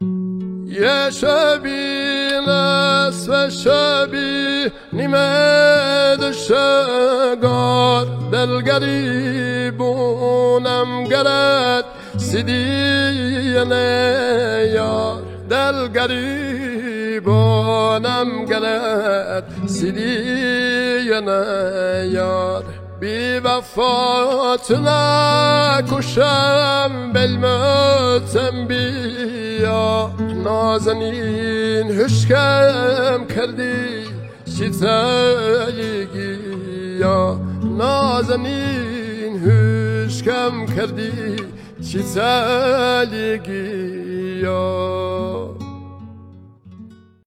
آهنگ لری